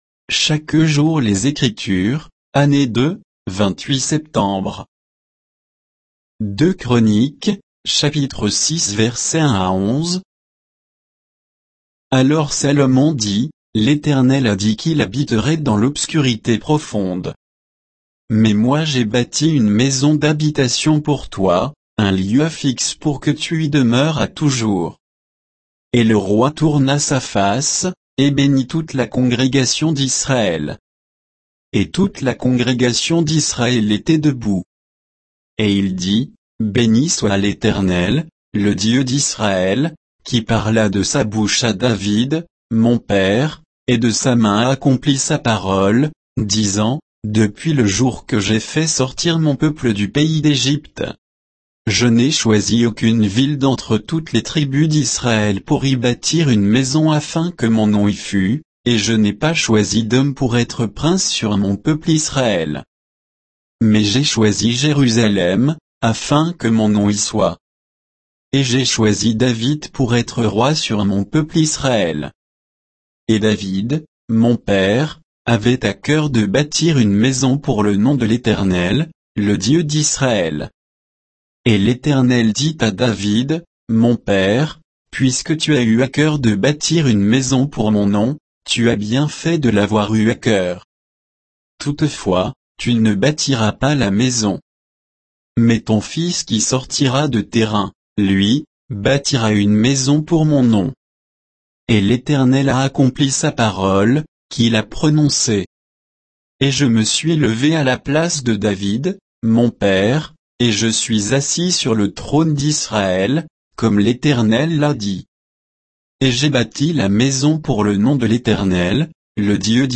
Méditation quoditienne de Chaque jour les Écritures sur 2 Chroniques 6